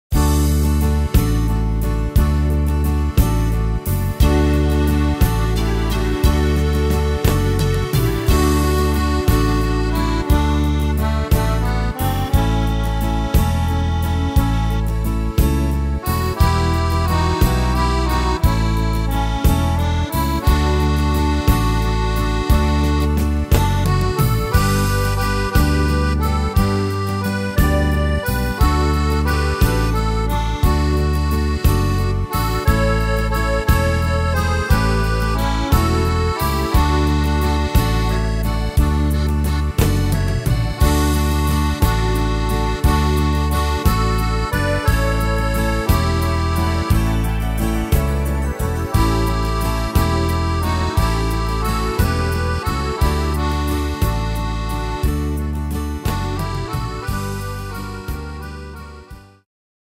Tempo: 59 / Tonart: D-Dur